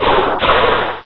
pokeemerald / sound / direct_sound_samples / cries / cacturne.aif